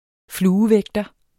Udtale [ ˈfluːəˌvεgdʌ ]